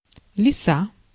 Ääntäminen
US : IPA : [ˈvık.sən]